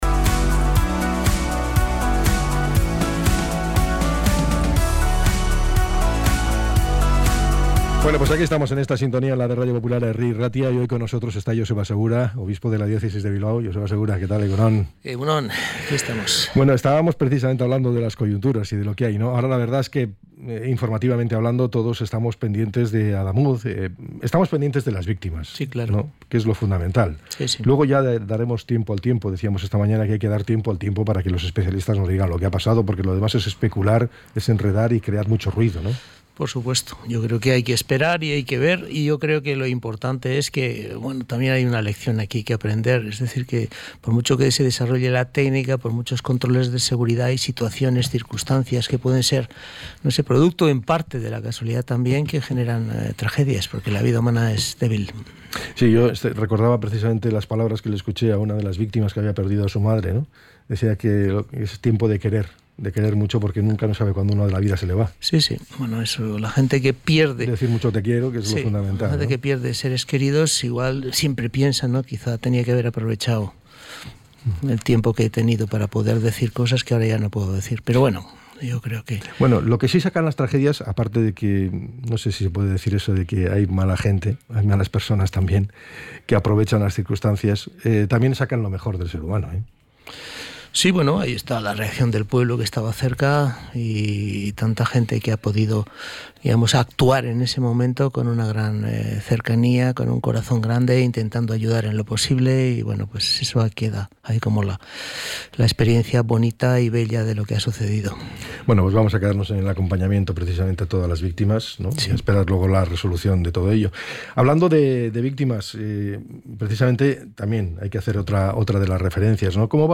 ENTREV.-JOSEBA-SEGURA.mp3